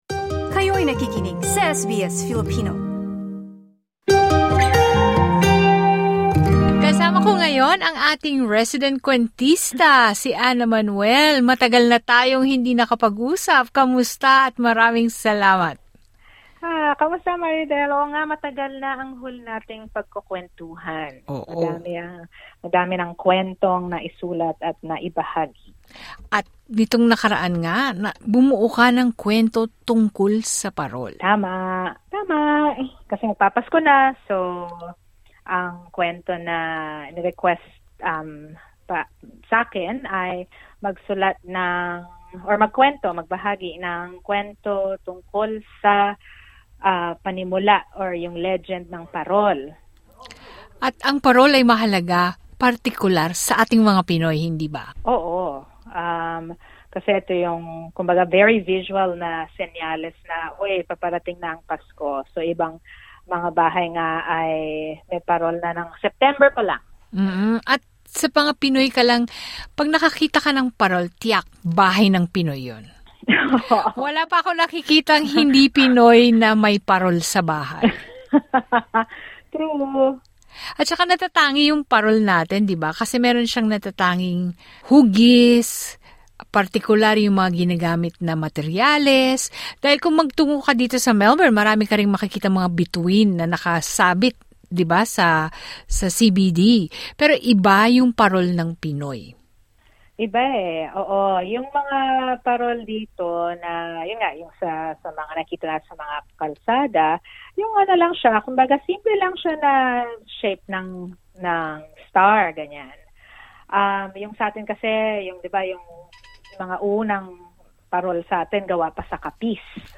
Story telling is one way of sharing values and traditions to children. Story telling can also be an inter-active activity.